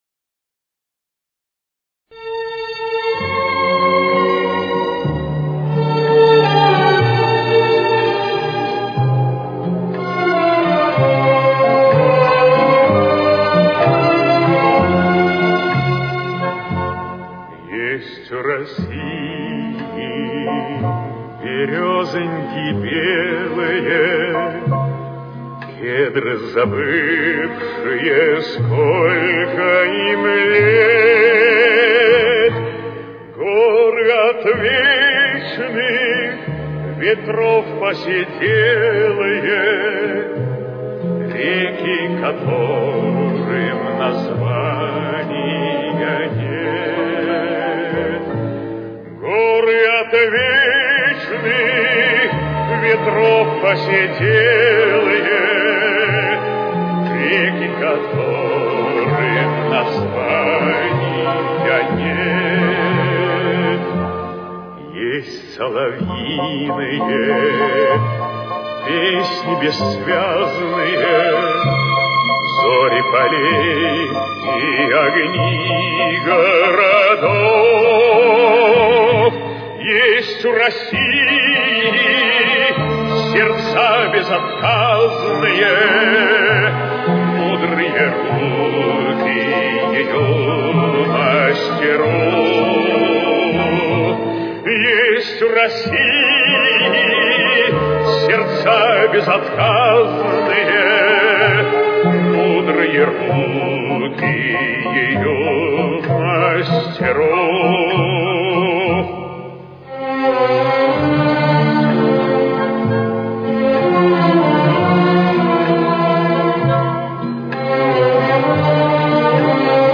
Фа мажор. Темп: 64.